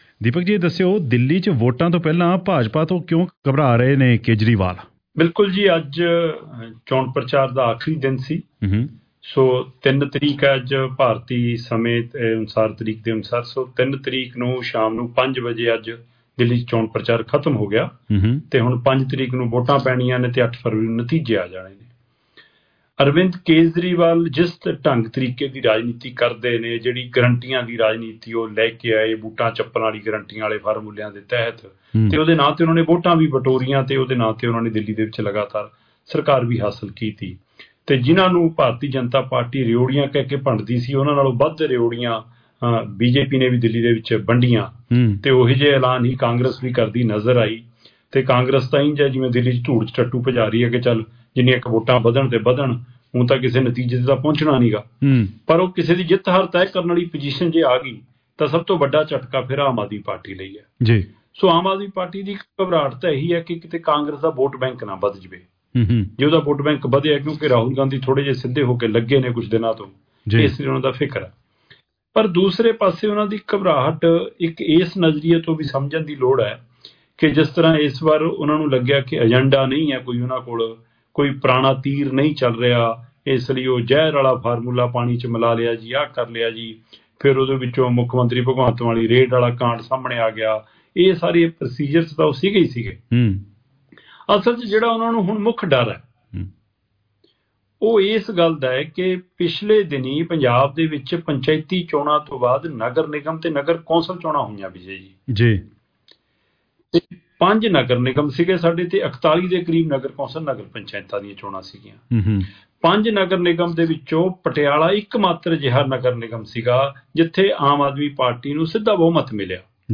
lead engaging discussions and provide in-depth analysis of the latest political developments.